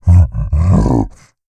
MONSTER_Exhausted_12_mono.wav